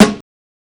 SNARE PATROL.wav